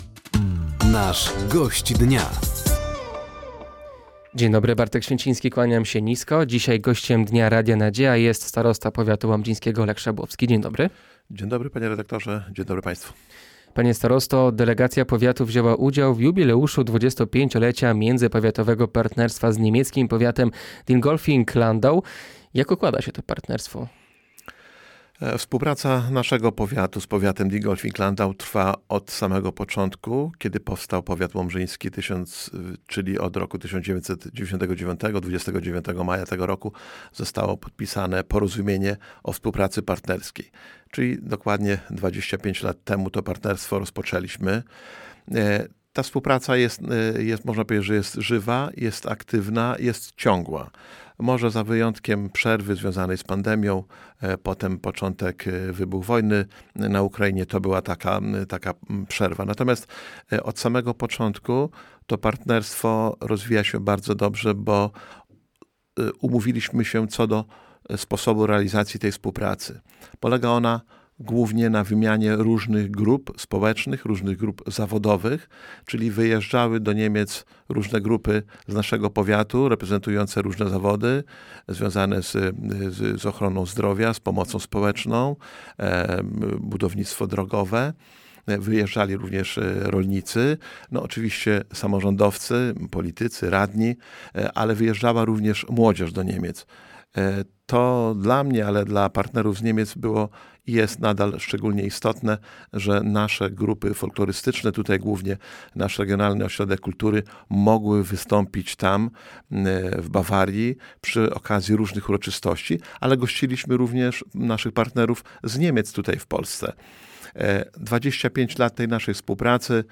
Gościem Dnia Radia Nadzieja był starosta powiatu łomżyńskiego Lech Szabłowski. Tematem rozmowy był jubileusz 25-lecia międzypowiatowego partnerstwa z powiatem Dingolfing-Landau oraz CPK.